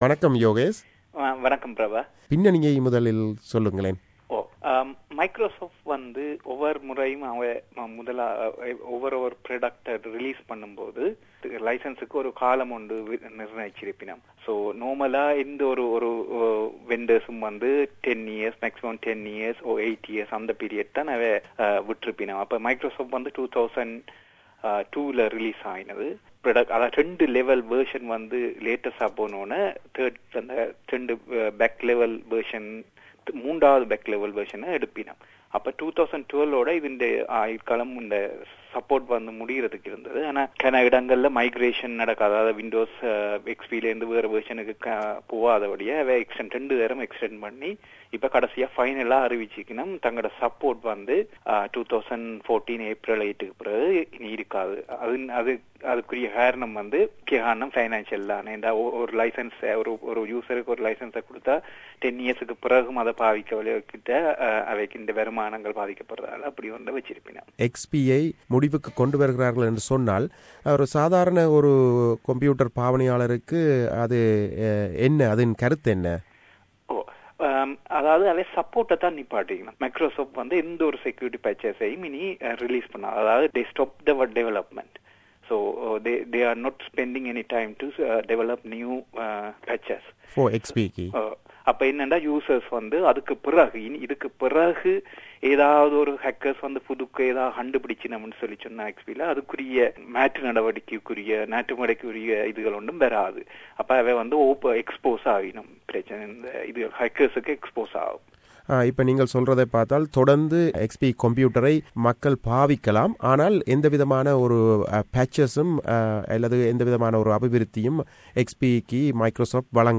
Microsoft announced after a 12 years that the support for Windows XP will end. What does it mean to the user's point of view? A short discussion with an expert